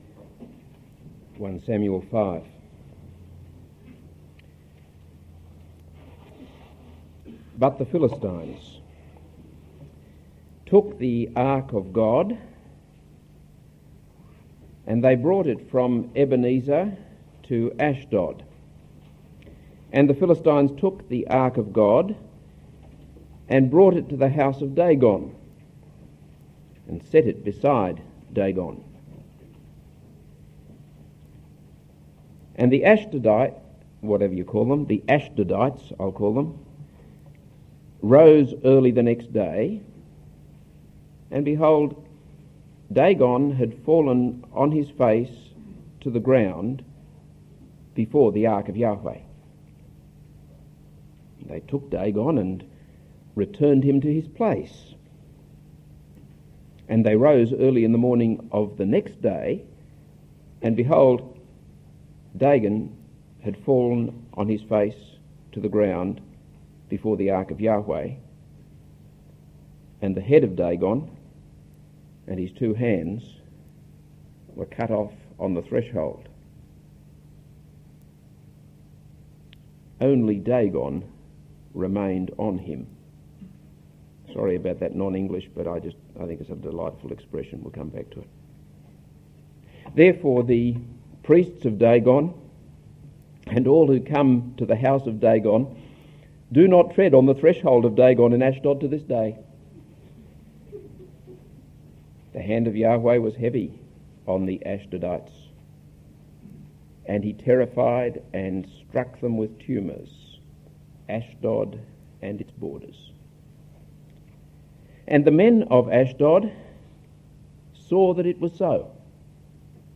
This is a sermon on 1 Samuel 5.